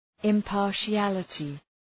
Shkrimi fonetik{ım,pɑ:rʃı’ælətı}